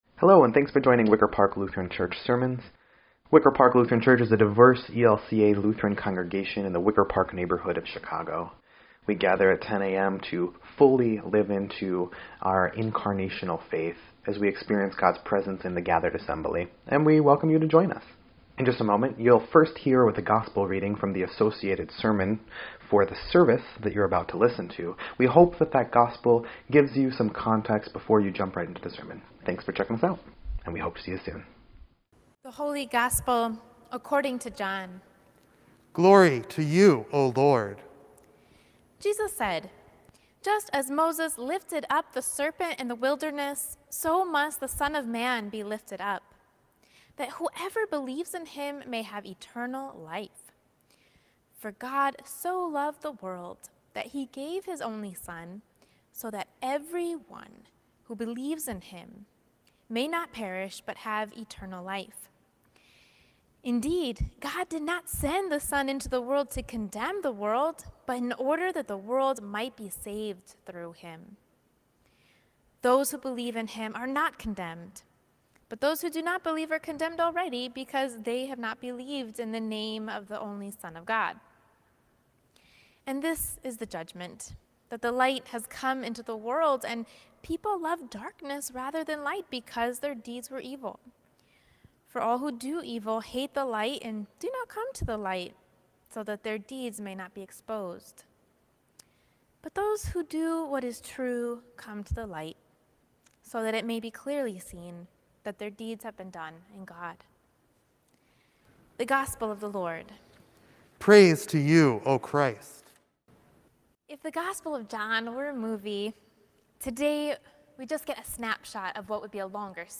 3.14.21-Sermon_EDIT.mp3